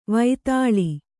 ♪ vaitāḷi